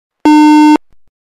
Ошибка детектора